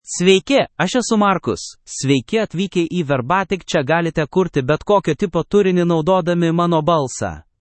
MaleLithuanian (Lithuania)
MarcusMale Lithuanian AI voice
Marcus is a male AI voice for Lithuanian (Lithuania).
Voice sample
Marcus delivers clear pronunciation with authentic Lithuania Lithuanian intonation, making your content sound professionally produced.